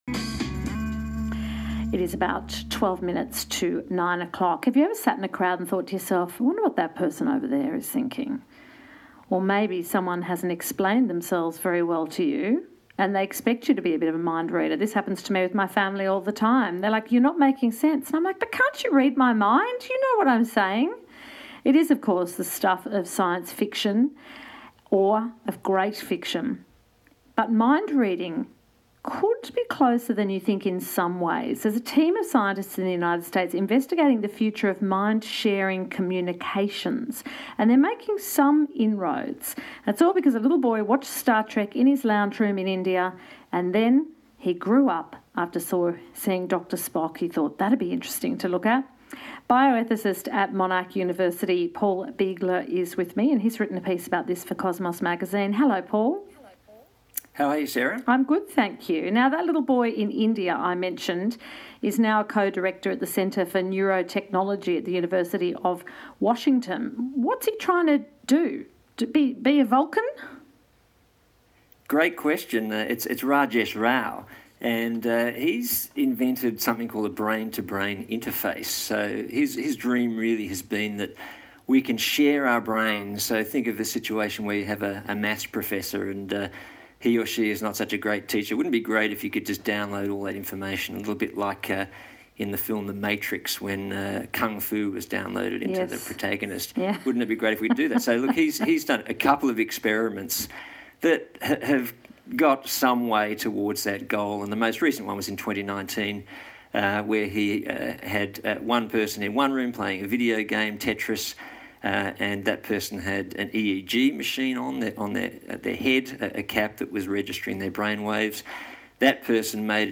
ABC radio interview on brain to brain interfaces